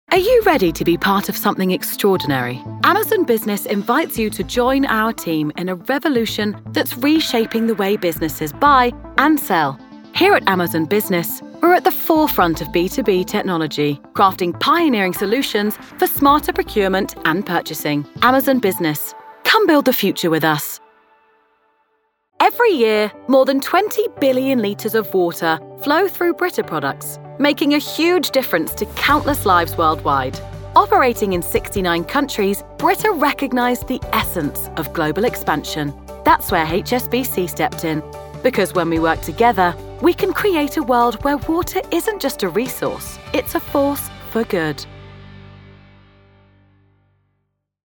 Inglés (Británico)
Versátil, Amable, Natural
Corporativo
Her voice is youthful, husky, relatable, and authentic, and her natural accent is a London/Estuary accent.